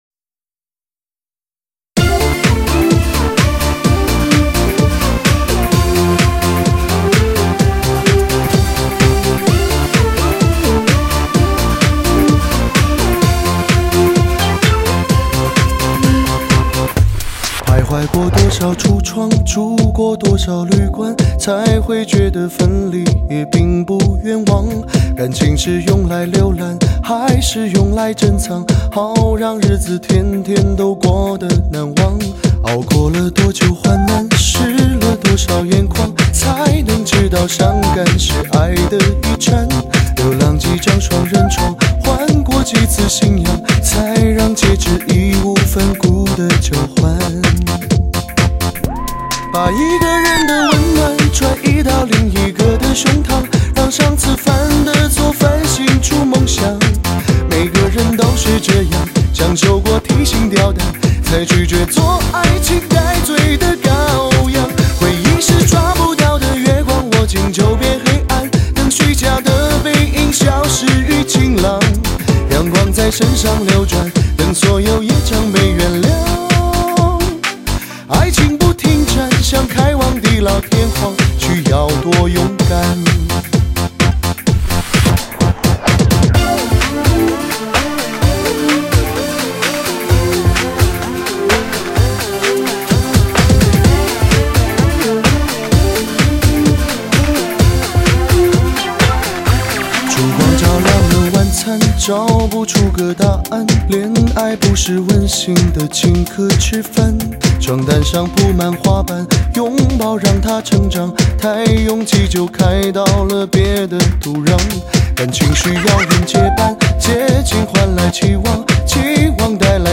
动感时尚劲爆无敌，至HIGH舞曲疯狂派对，引领全新热舞狂潮
激情动感 震撼刺激
劲爆的节拍、跳跃的旋律、强大的气流冲击，